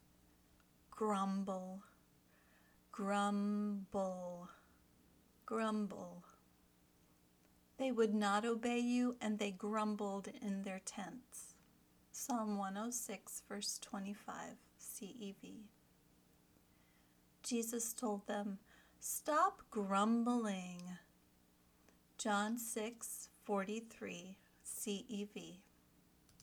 ˈgrʌm bəl  (verb)
vocabulary word – grumble